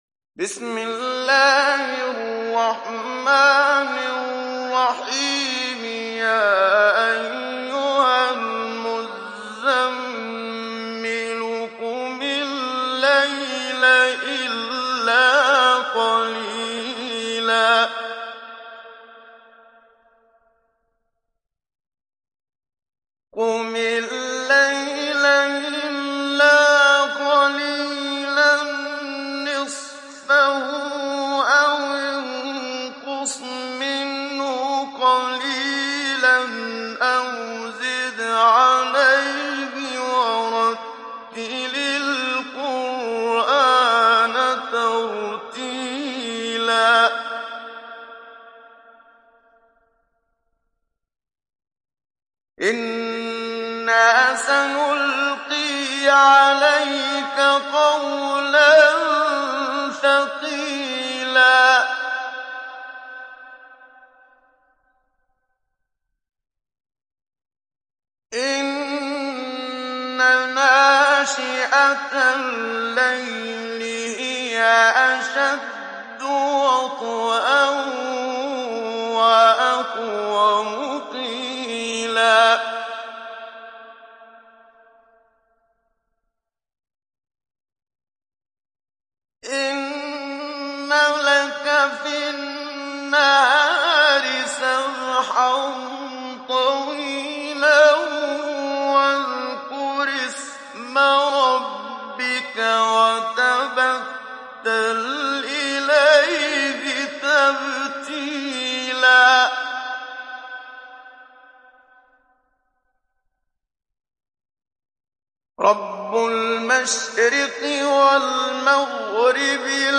Télécharger Sourate Al Muzzammil Muhammad Siddiq Minshawi Mujawwad